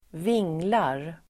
Uttal: [²v'ing:lar]